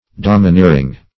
Domineering \Dom`i*neer"ing\, a.